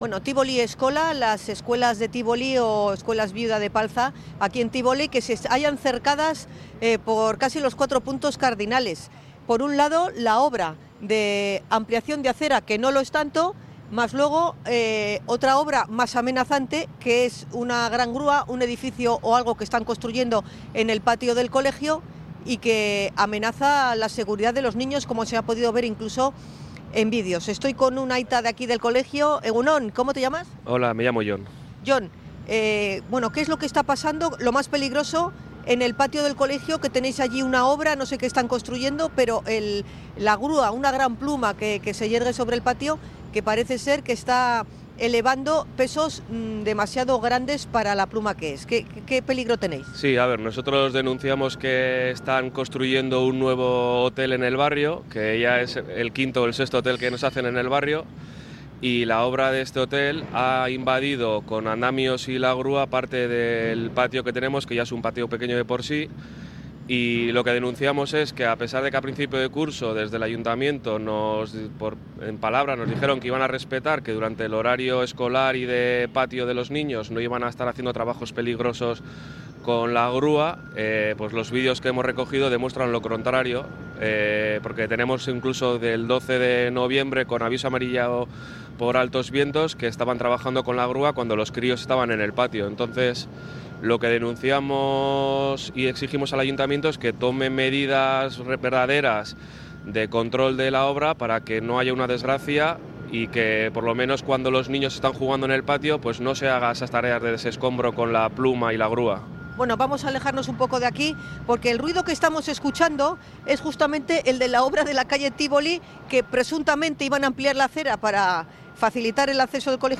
Hablamos con varios aitas en la puerta de la escuela